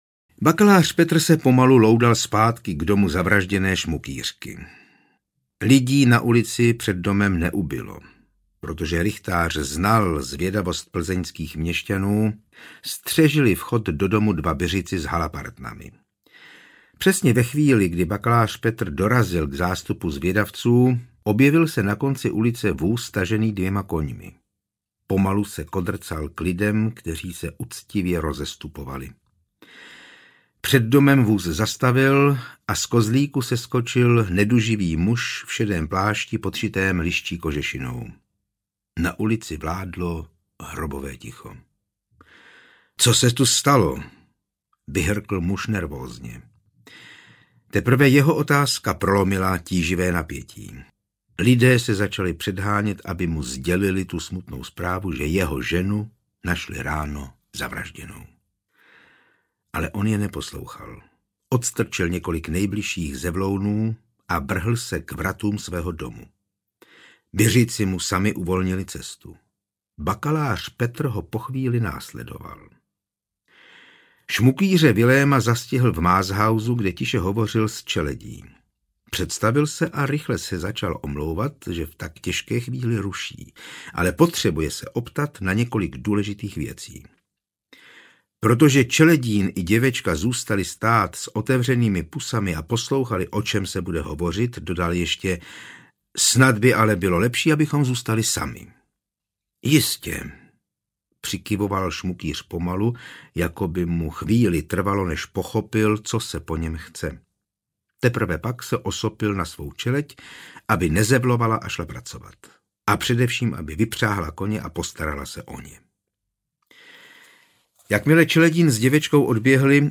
Speciální edice na přání posluchačů. Bez hudebních předělů a podkresů.
Ukázka z knihy